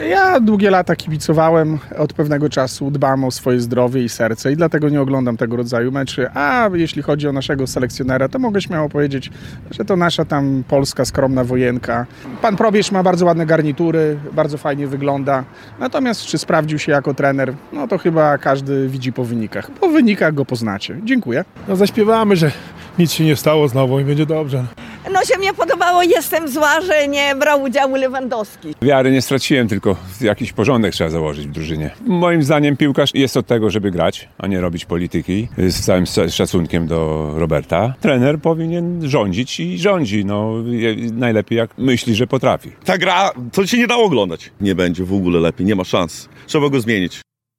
Sonda: Czy zwolnić Michała Probierza? Mieszkańcy Łomży wyrazili się jasno
Zapytaliśmy mieszkańców Łomży o wrażenia po porażce w Helsinkach i o to, czy należy zwolnić selekcjonera Michała Probierza.